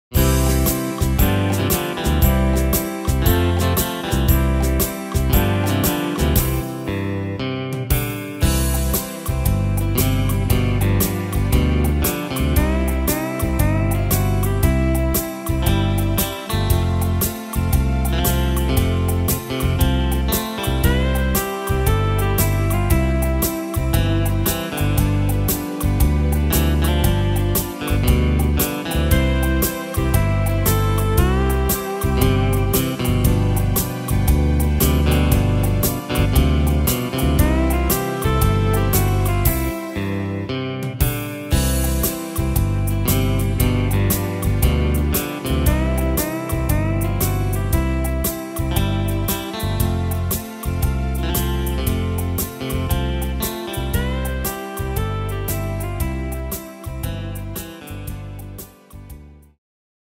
Tempo: 116 / Tonart: C-Dur